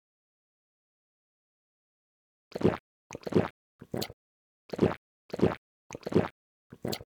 drink2.ogg